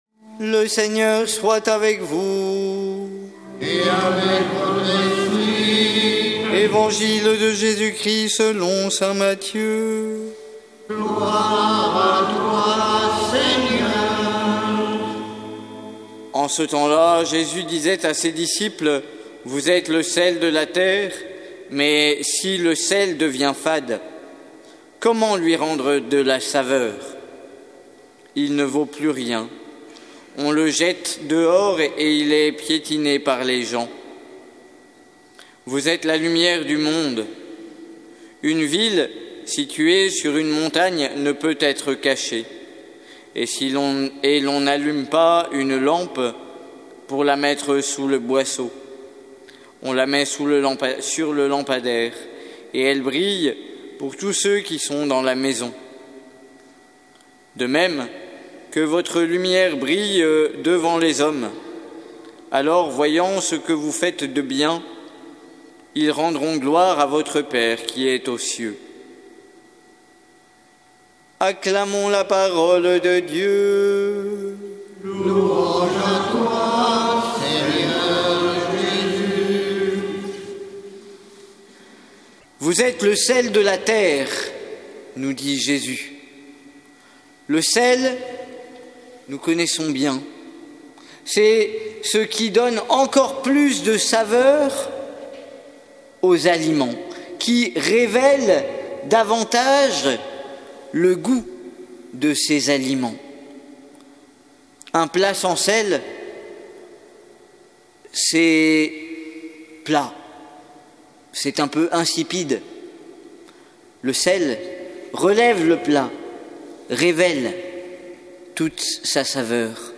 Évangile de Jésus Christ selon saint Matthieu avec l'homélie